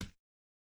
Bare Step Stone Hard C.wav